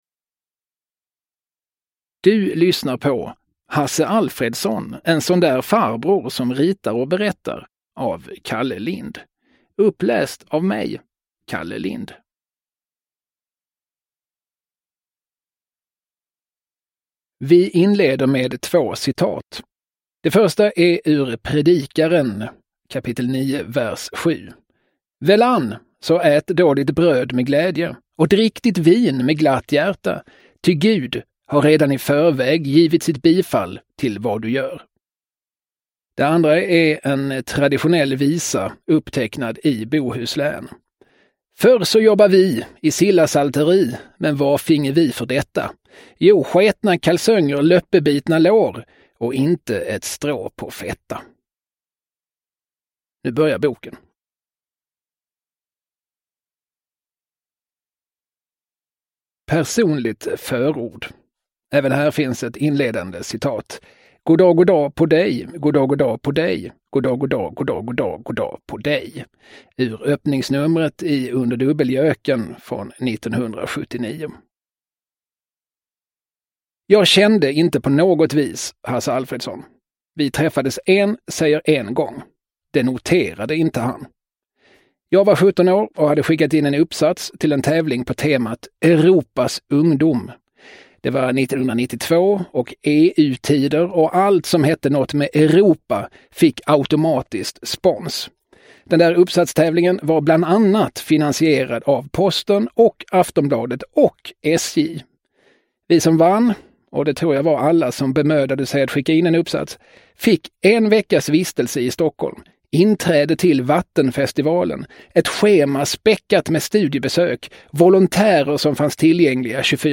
Hasse Alfredson : en sån där farbror som ritar och berättar – Ljudbok – Laddas ner